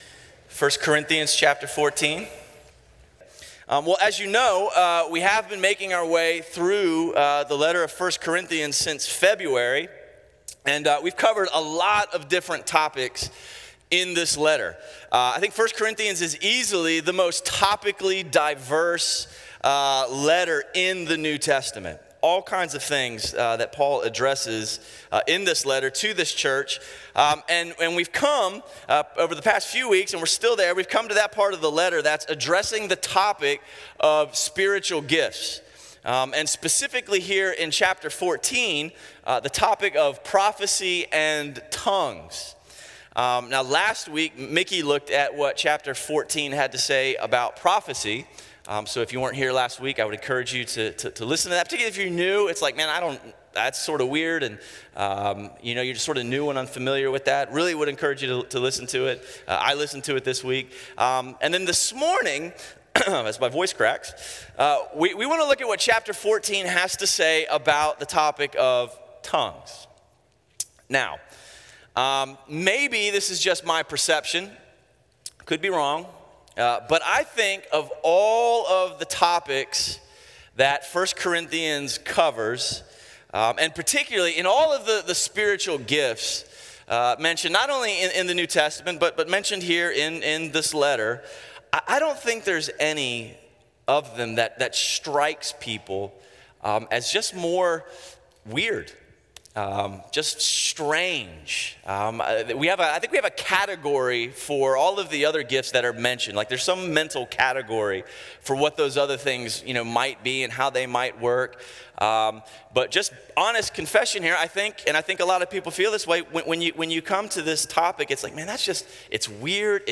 A message from the series "1-1-Six."
A Sunday morning series on 1 Corinthians at Crossway Community Church.